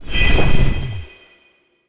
magic_flame_of_light_03.wav